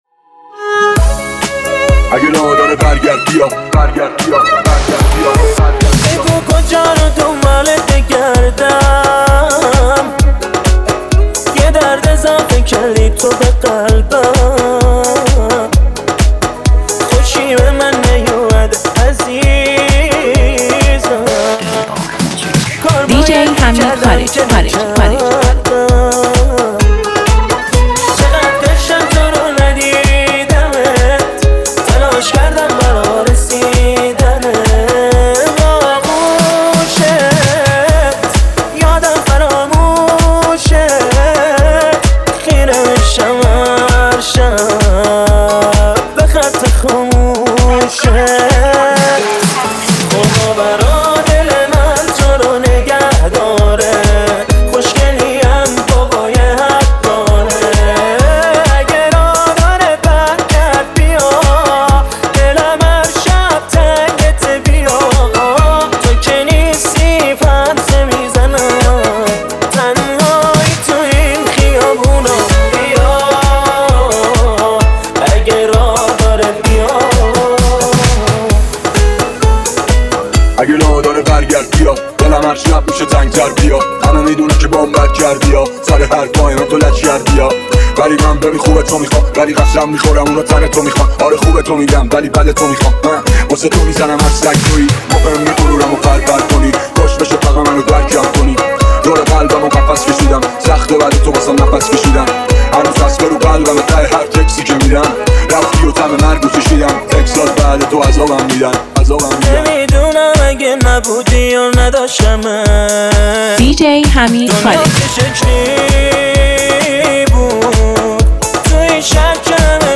نسخه ریمیکس